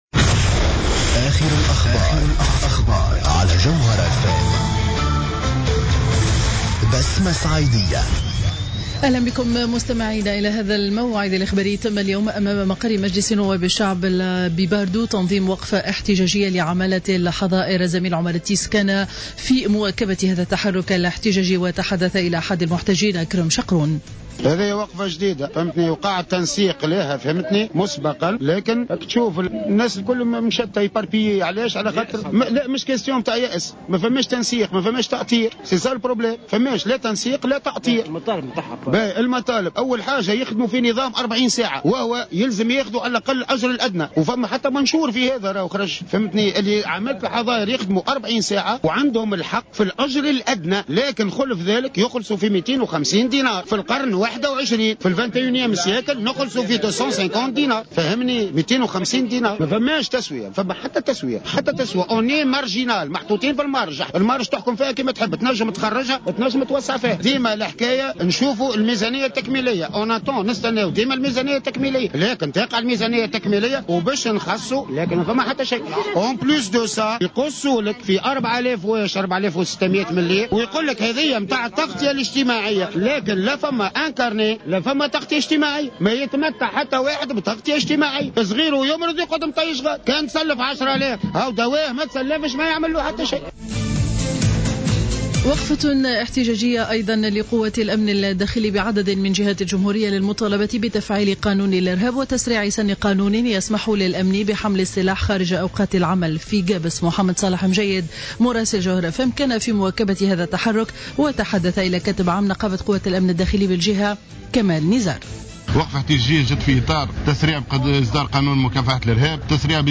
نشرة أخبار منتصف النهار ليوم الاربعاء 07-01-15